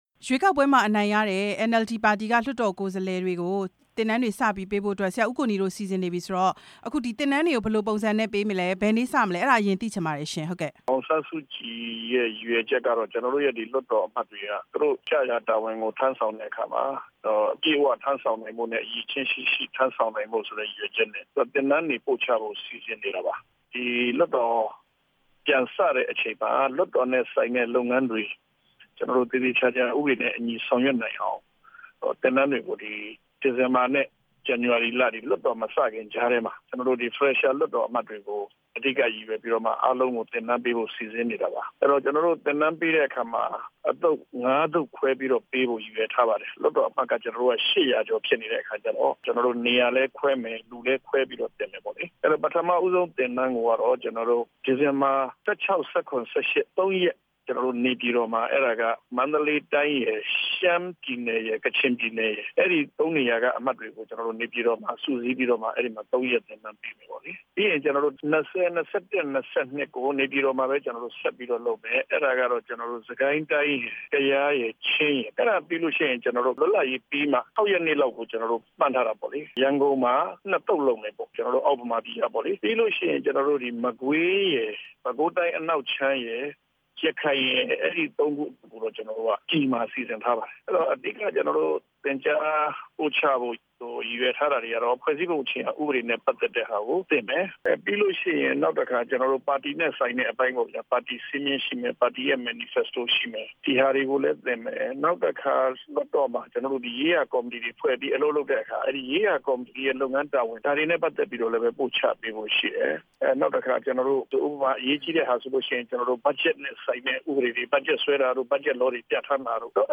တရားလွှတ်တော်ရှေ့နေ ဦးကိုနီနဲ့ မေးမြန်းချက်